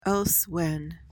PRONUNCIATION: (ELS-wen) MEANING: adverb: At another time.